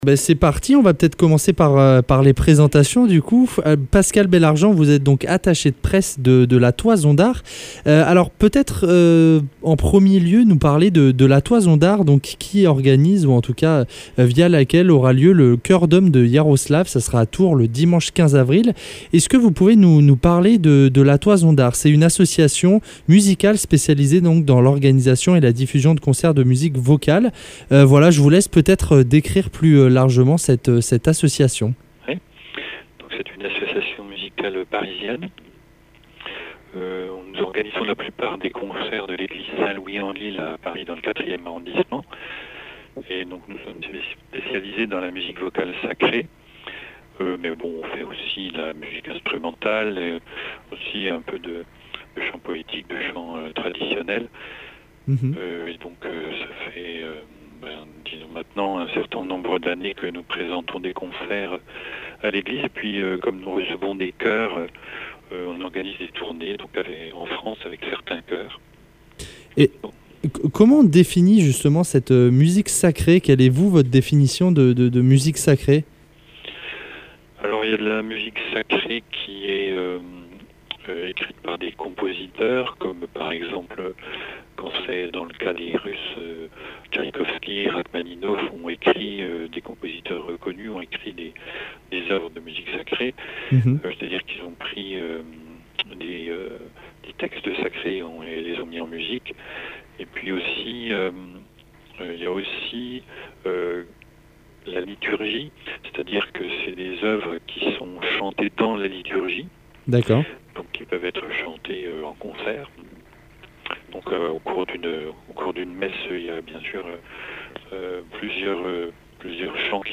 Interview !